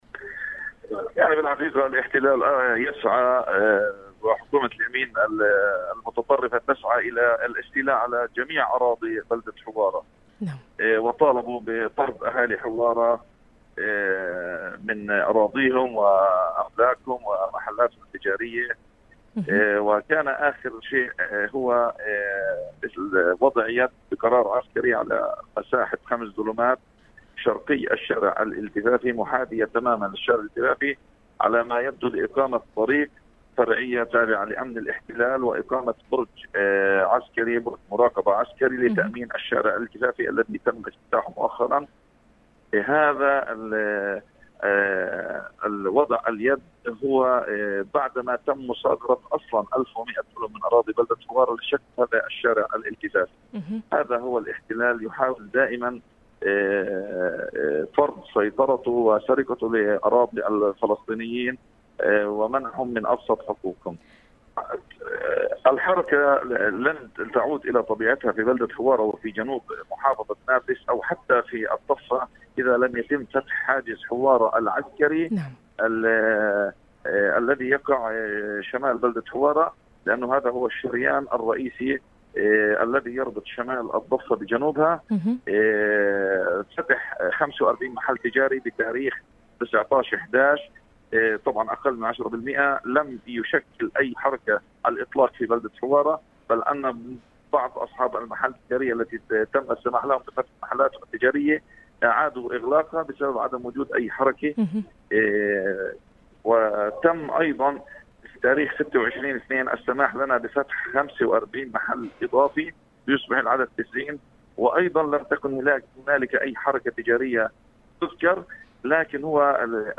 وقال رئيس بلدية حوارة معين ضميدي لـ “شباب اف ام”، إن سلطات الاحتلال سلمت قرارا بالاستيلاء  على ٤٨٠٠ متر مربع من أراضي المنطقة الشرقية من البلدة، بحجة  أغراض عسكرية، لإقامة طريق فرعية للاحتلال وبرج مراقبة عسكري لتأمين الشارع الإلتفافي الذي تم افتتاحه مؤخرا في المنطقة.